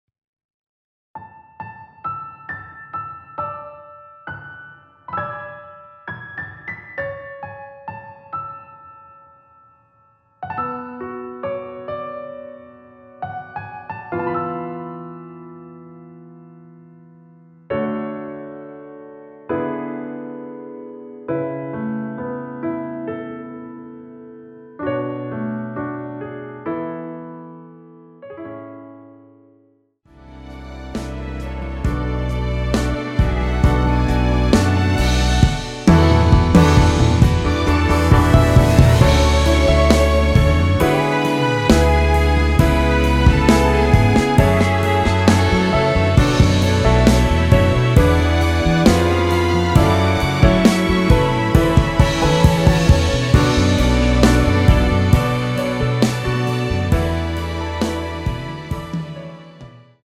여성분이 부르실수 있는 키의 MR입니다.
원키에서(+3)올린 MR입니다.
앞부분30초, 뒷부분30초씩 편집해서 올려 드리고 있습니다.
중간에 음이 끈어지고 다시 나오는 이유는